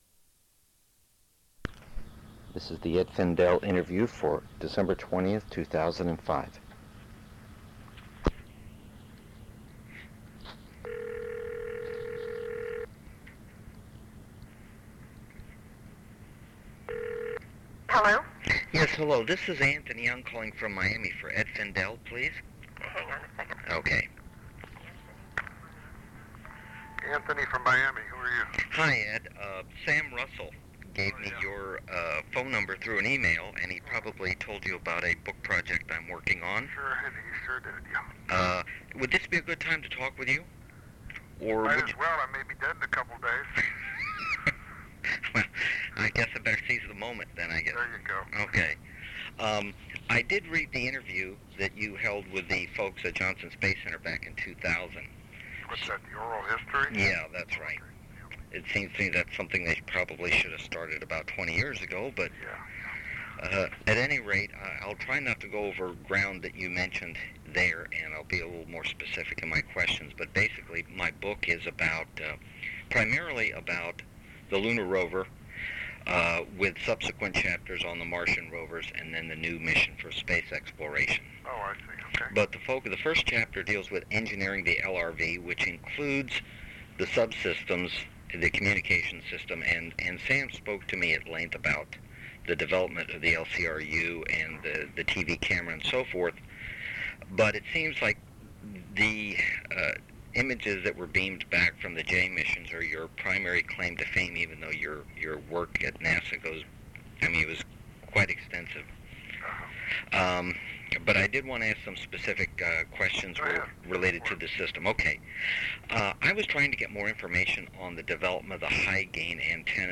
Interviews
Oral History